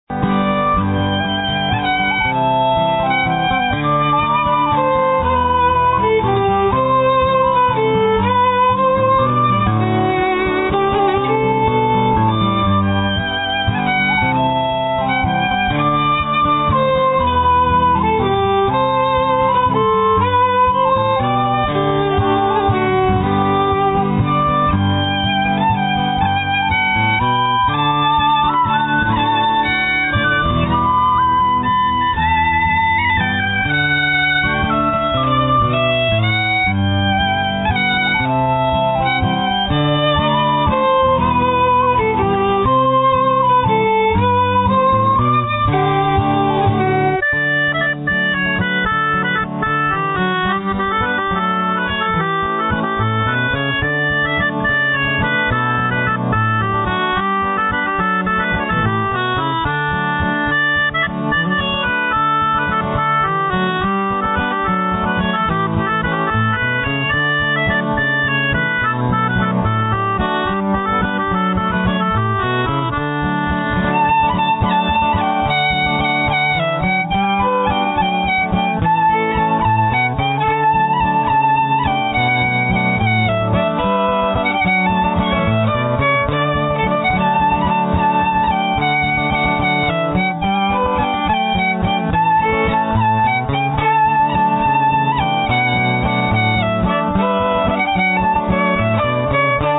Traditional Irish/Celtic music
Bodhràn
Fiddle
Guitar
Accordion
Soprano D Whistle